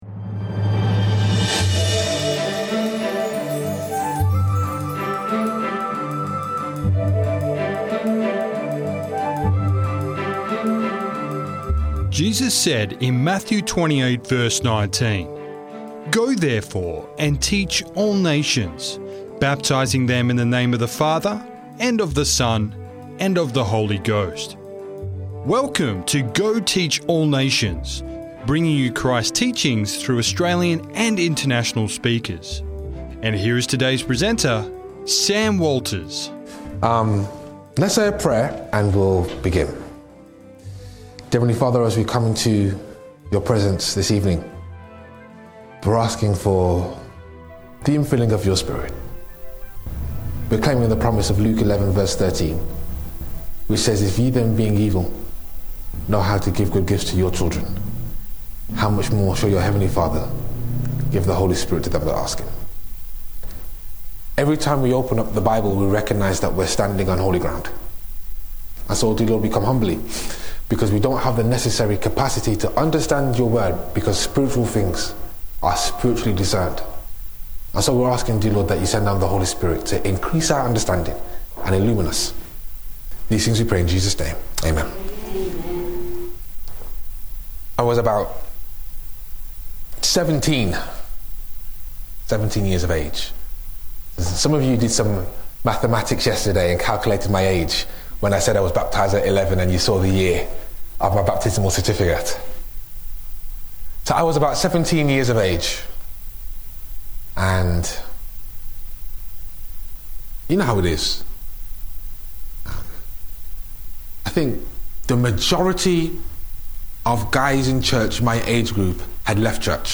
On the Brink of Canaan - Sermon Audio 2603